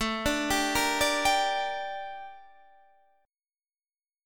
Gm/A chord